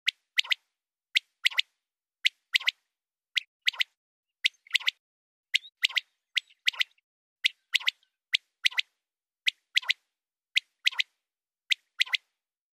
Звуки перепела
Пение перепела звучит